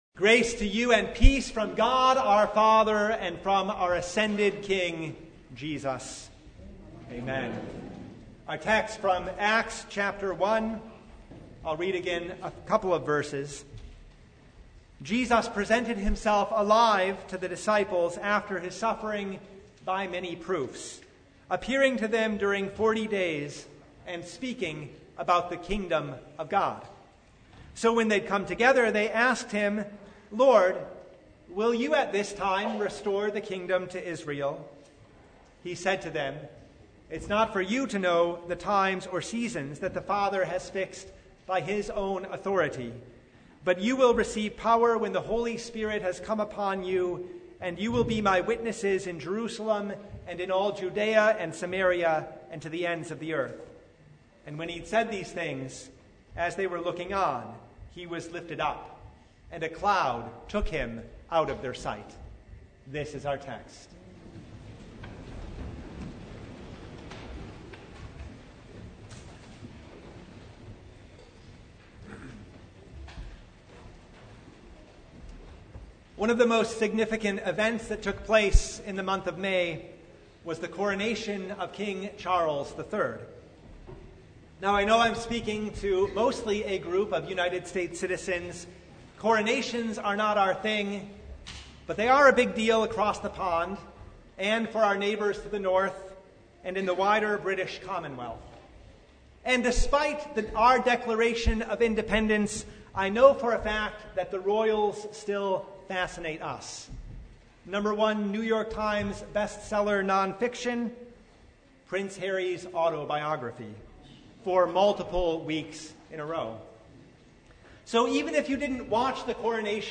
Sermon from The Feast of the Ascension of our Lord (2023)
Topics: Sermon Only